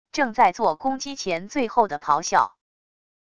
正在做攻击前最后的咆哮wav音频